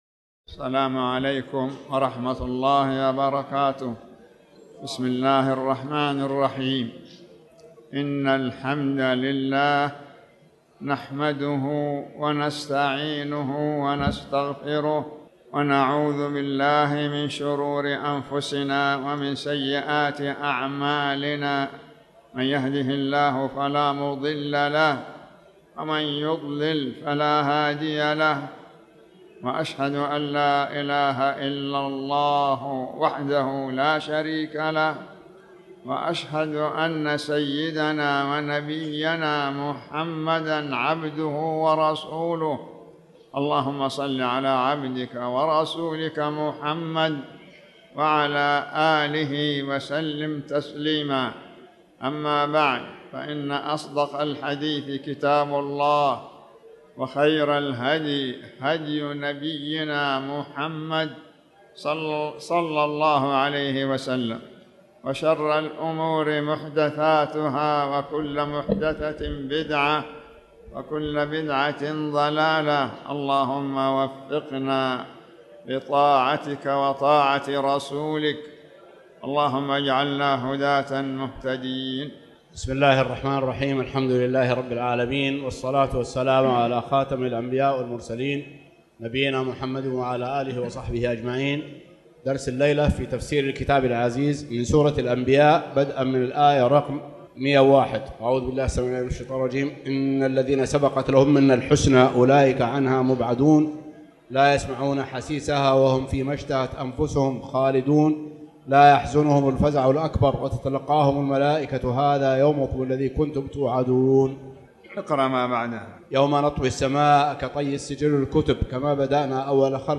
تاريخ النشر ١٦ ربيع الثاني ١٤٣٩ هـ المكان: المسجد الحرام الشيخ